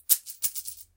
звенящие монеты
zvenyashie_moneti_p9u.mp3